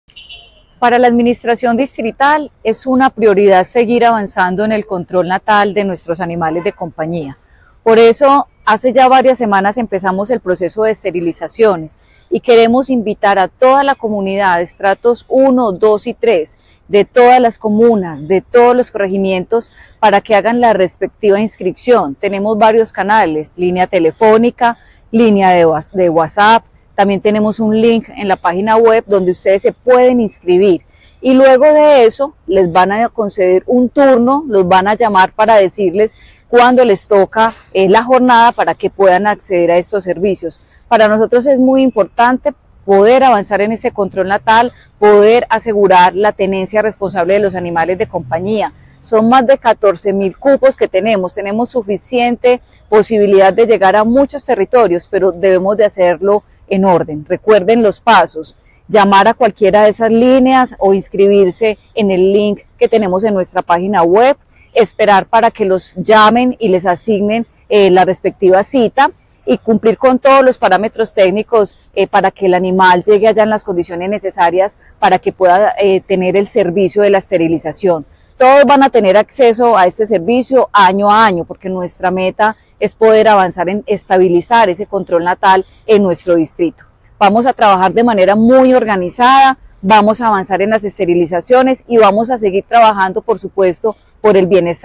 Palabras de Ana Ligia Mora Martínez, secretaria de Medio Ambiente